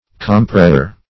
Compressure \Com*pres"sure\ (?; 135), n.